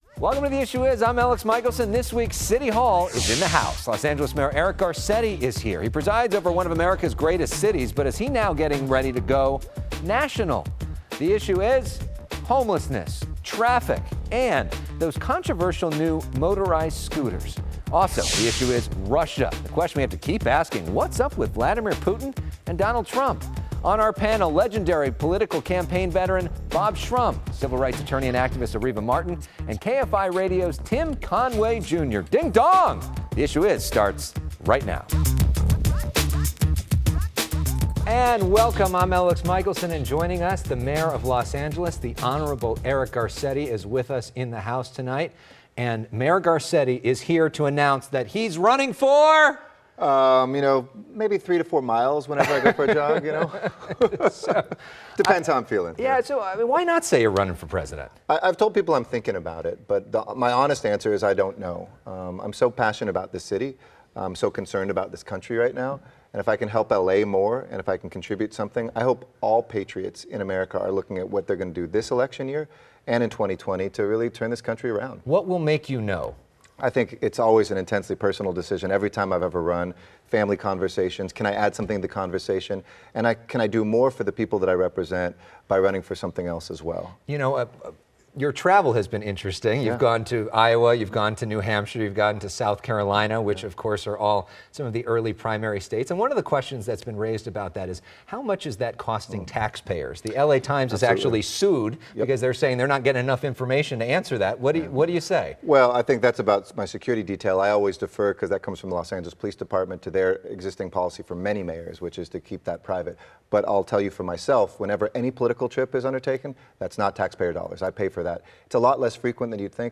The mayor also discusses traffic, motorized scooters and his role models. The accomplished jazz pianist also plays a little in our studio.